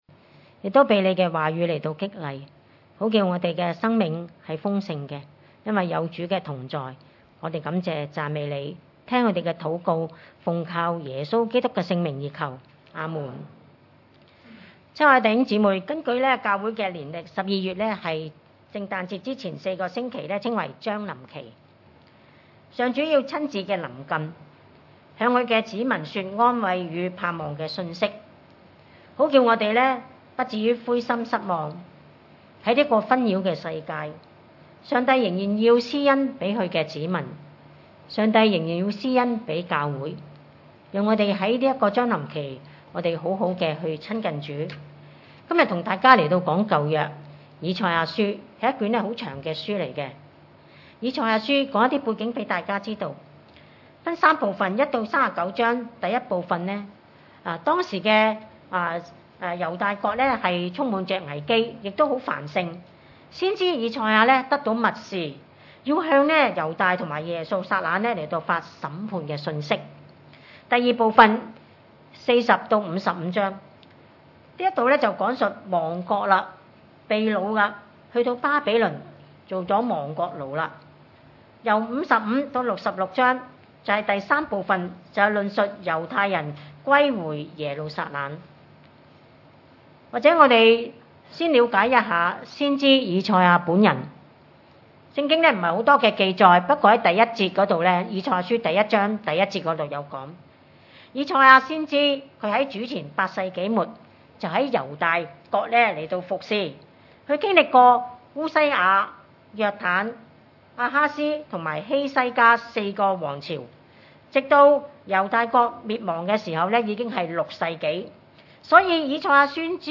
經文: 以賽亞書40:1-11 崇拜類別: 主日午堂崇拜 1 你們的 神說、你們要安慰、安慰我的百姓。